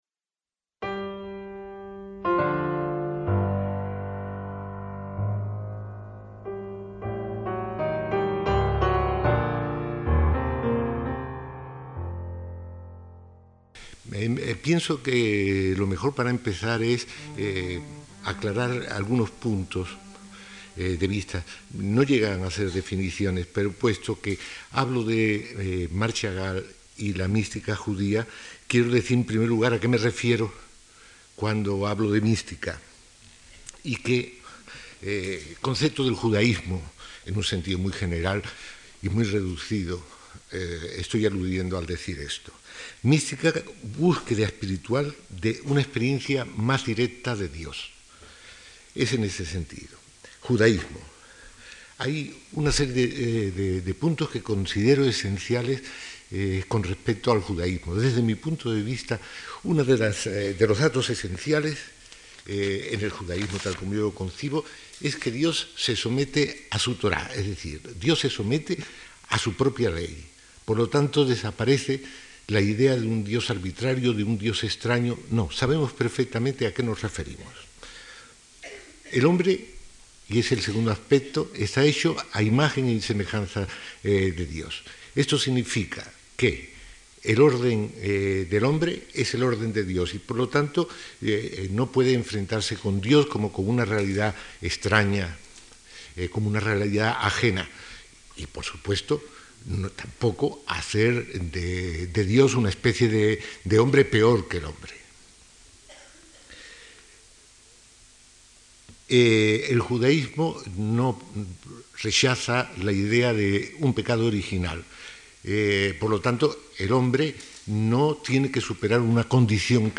ACTOS EN DIRECTO - De los archivos sonoros de la Fundación Juan March, hemos recuperado una serie de charlas en torno a la figura del pintor Marc Chagall de enero de 1999, coincidiendo con una exposición de sus obras.